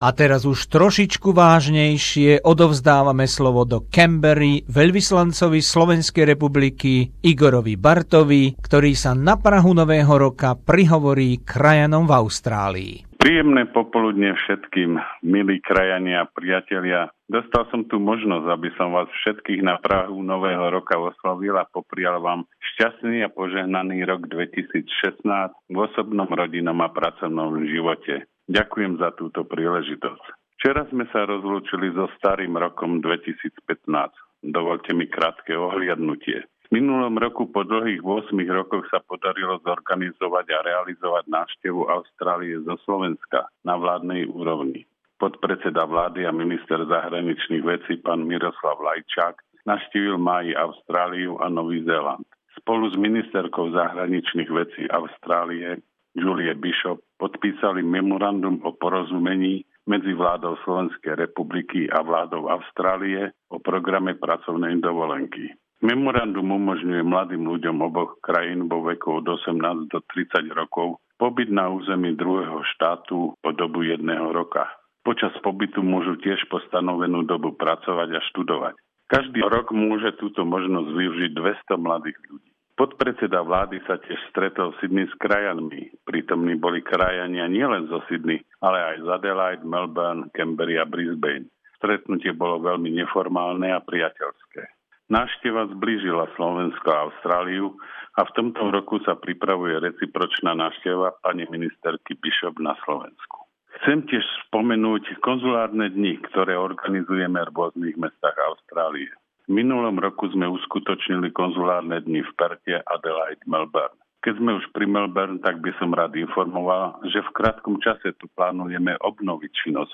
Príhovor Jeho Excelencie, dr. Igora Bartha, veľvyslanca SR v Canberre, ku krajanom v Austrálii, na Nový rok 2016 a v deň štátneho sviatku vzniku samostatnej SR